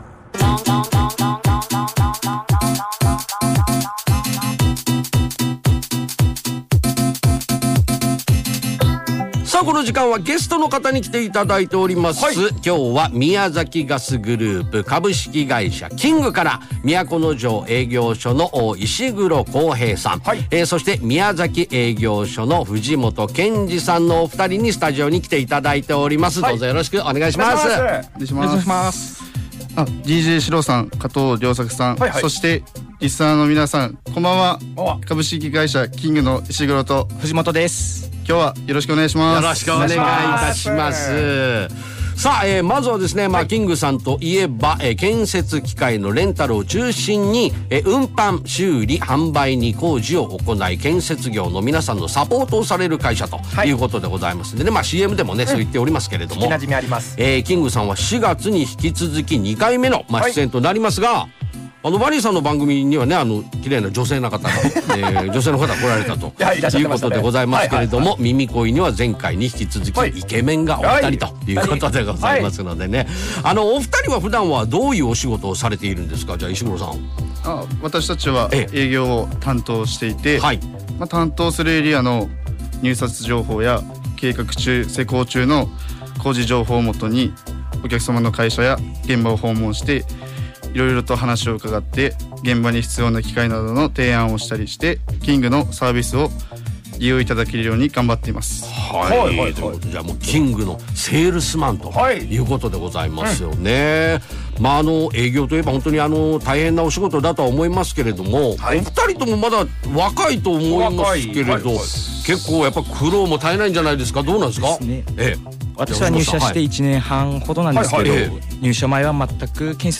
FM宮崎で企業紹介が放送されました。